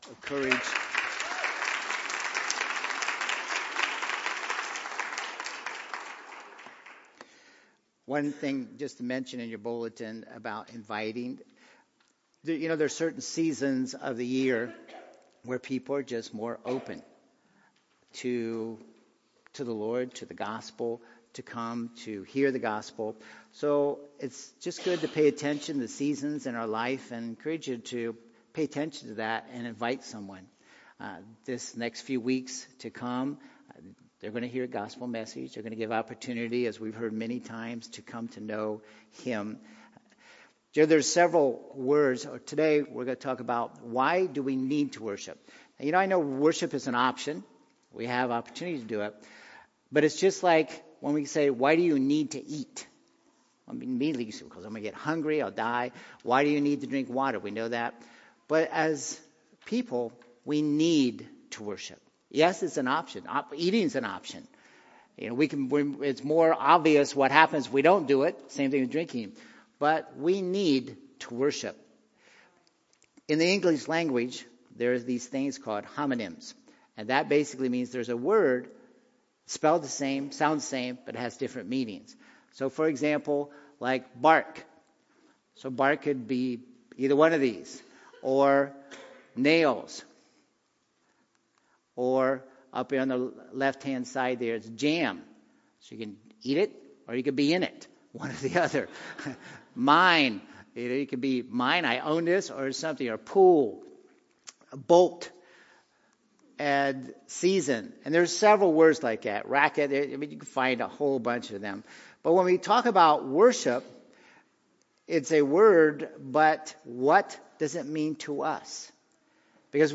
Sermons Sort By Date - Newest First Date - Oldest First Series Title Speaker Our Mission - COME!